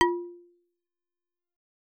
content/hifi-public/sounds/Xylophone/F2.L.wav at main